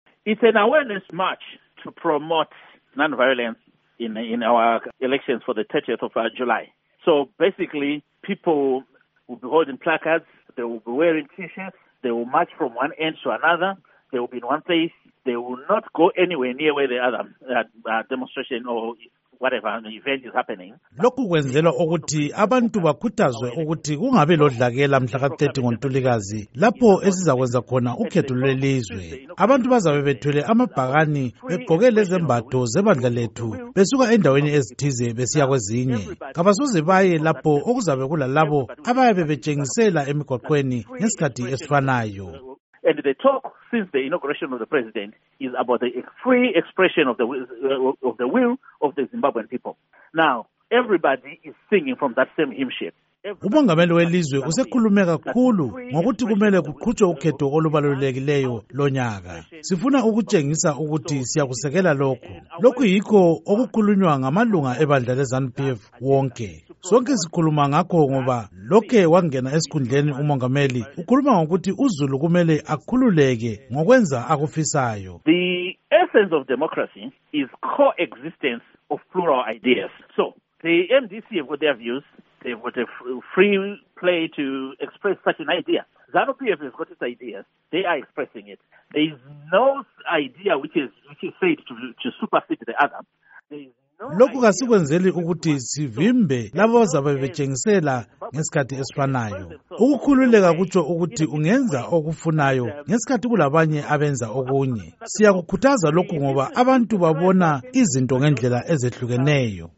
Ingxoxo Esiyenze LoMnu. Nick Mangwana